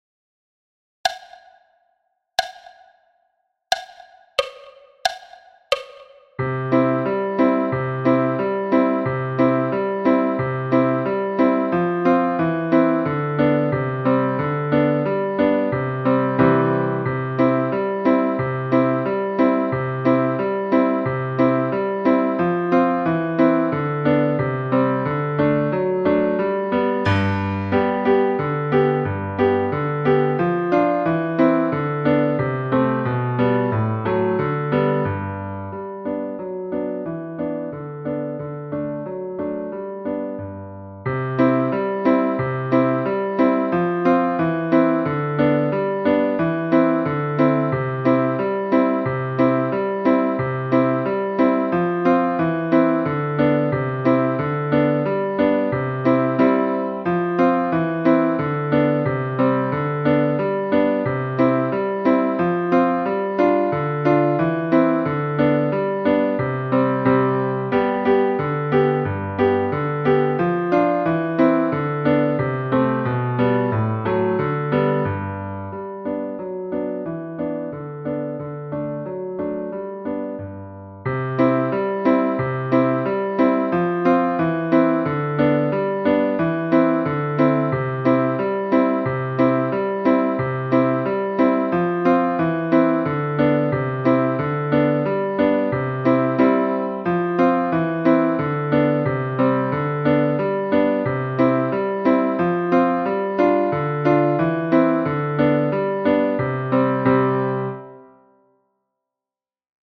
Xylophon – Polka – piano solo à 90 bpm
Xylophon-Polka-piano-solo-a-90-bpm.mp3